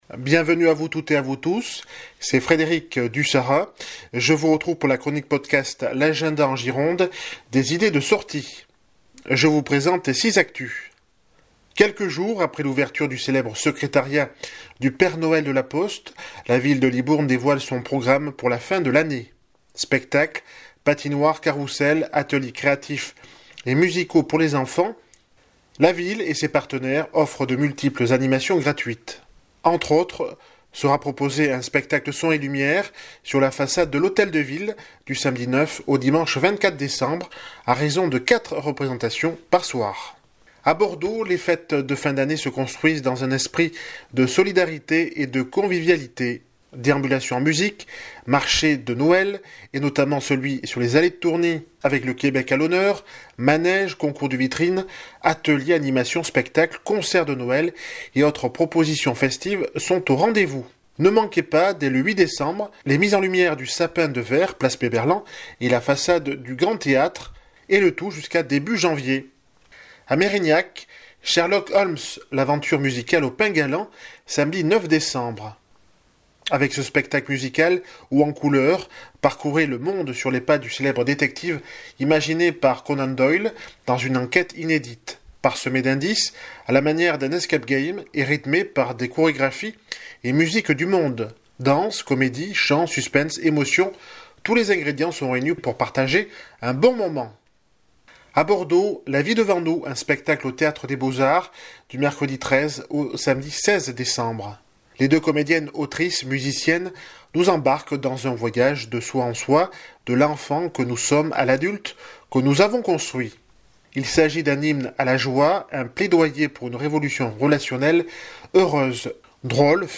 CHRONIQUE PODCAST DU 16 JUIN 2025